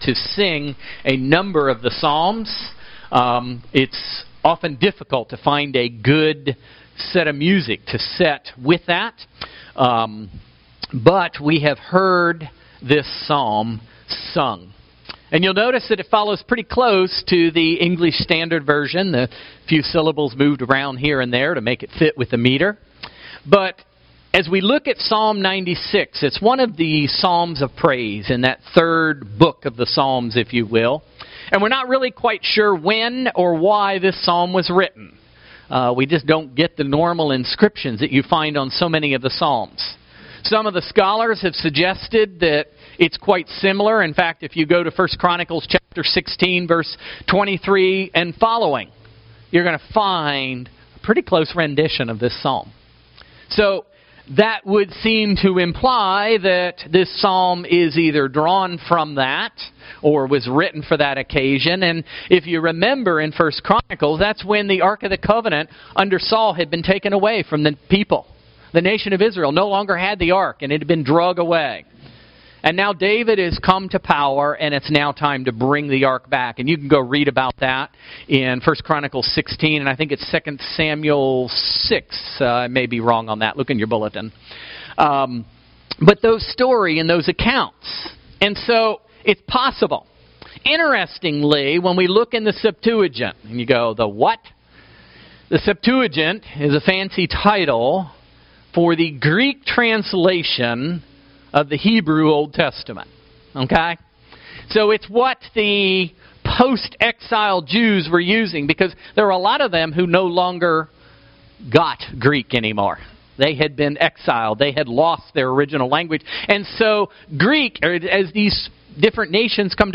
Psalm 11 Service Type: Sunday Morning Worship